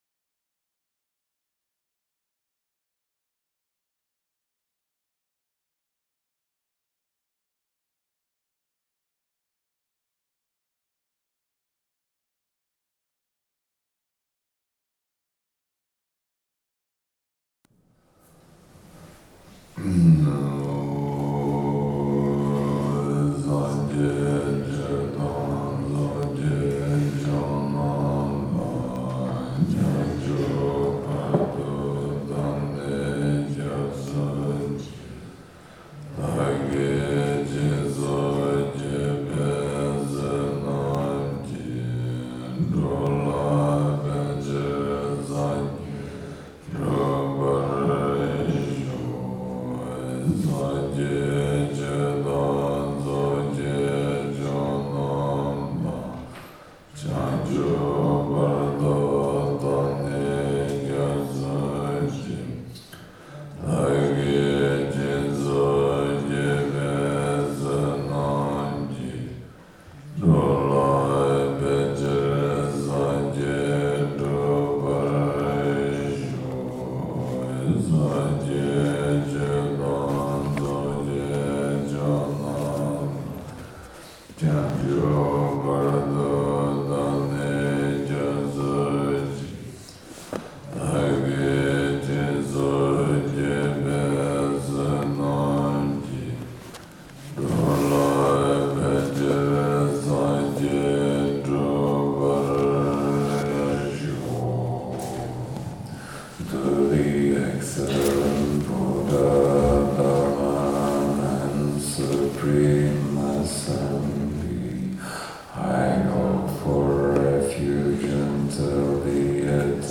This year we practiced in both Tibetan and English, which gave us the opportunity to connect to the original tibetan words and melody of this practice
This recording gives an impression of how we try to keep the atmosphere and feeling of the Tibetan original when we chant in English.